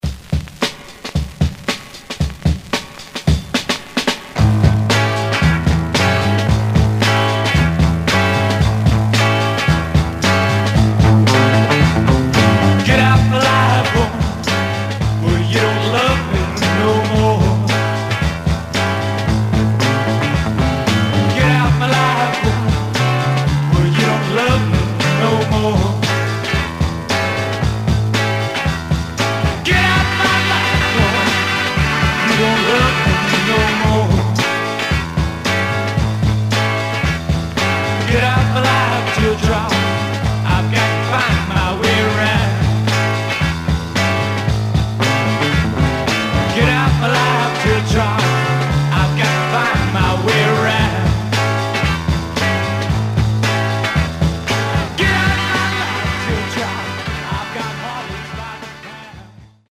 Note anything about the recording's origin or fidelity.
Surface noise/wear Stereo/mono Mono